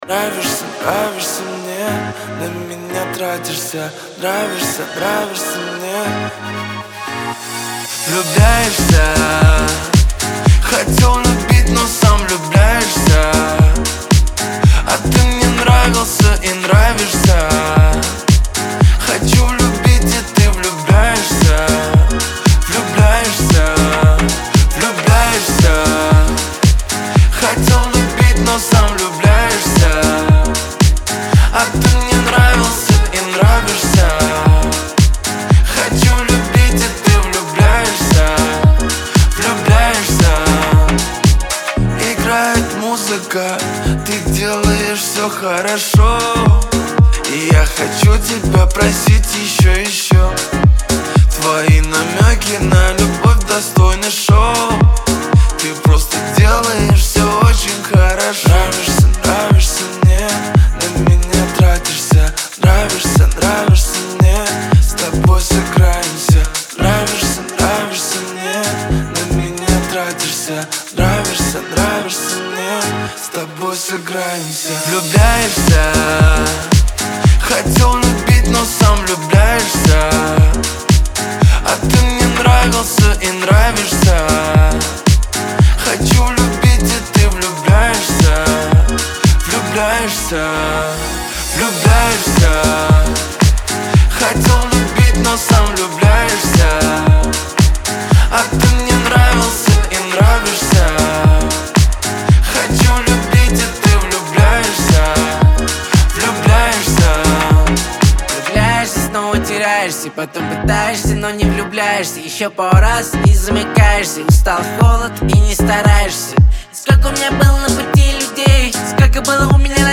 pop
дуэт